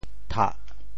墖 部首拼音 部首 土 总笔划 14 部外笔划 11 普通话 tǎ 潮州发音 潮州 tah4 白 中文解释 塔〈名〉 (形声。
thah4.mp3